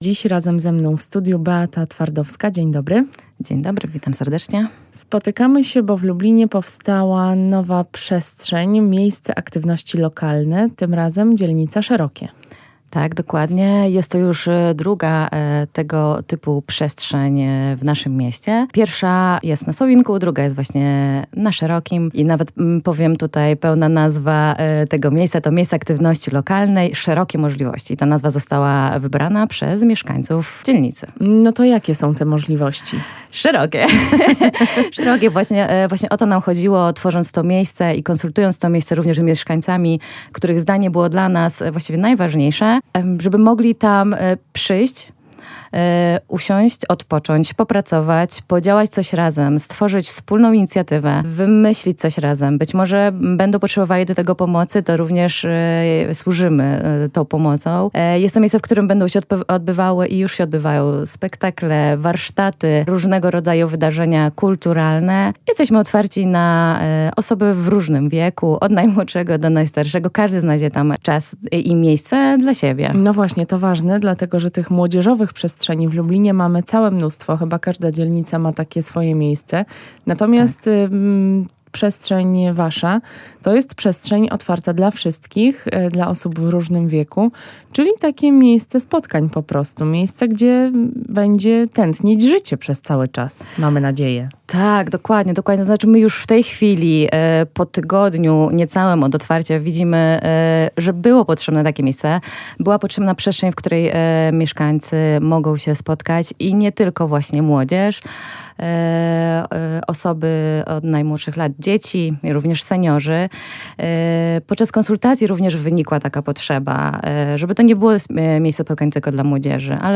O szczegółach w rozmowie